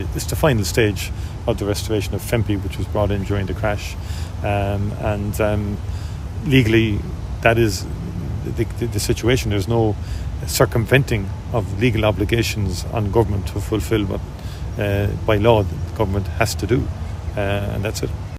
But Taoiseach Micheál Martin says they’re legally obligated to restore pay: